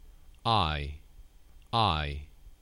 Haz clic para escuchar la pronunciación de las palabras: